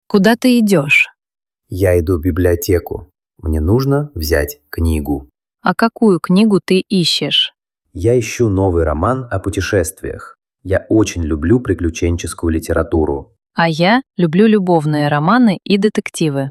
Dialogue pratique avec l'emploi de l'accusatif en russe
accusatif-russe-dialogue.mp3